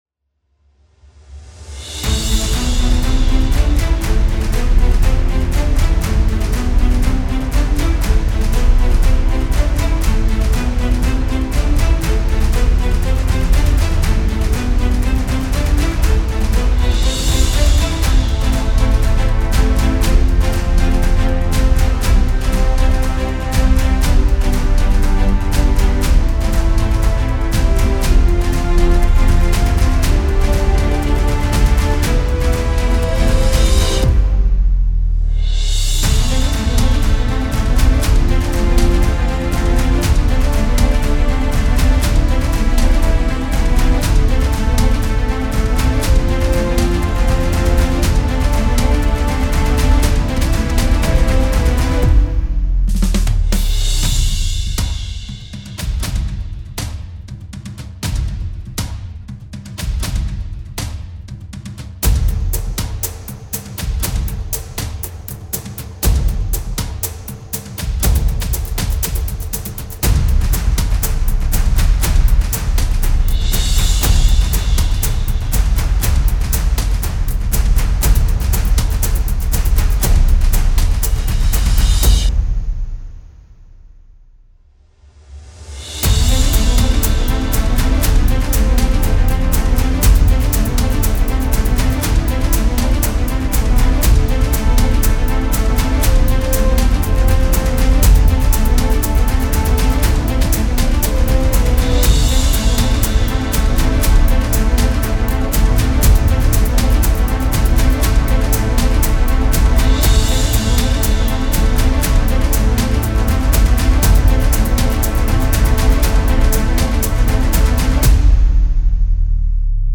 Epic soundtrack